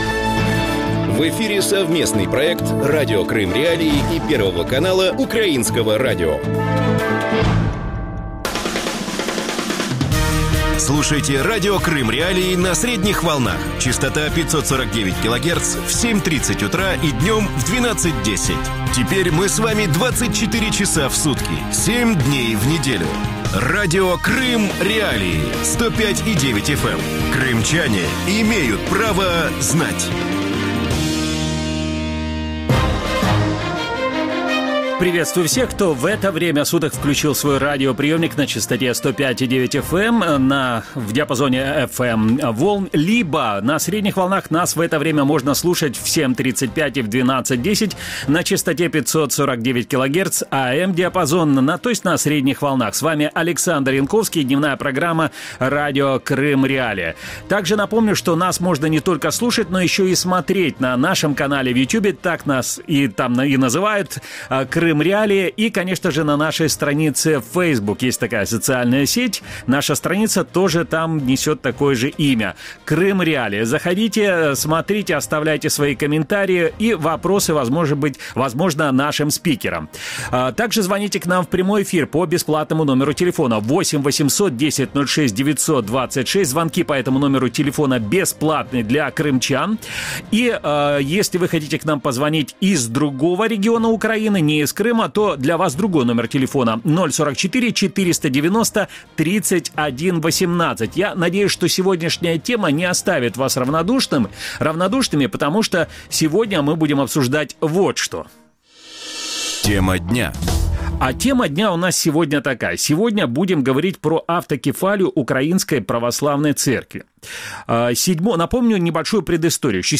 ток-шоу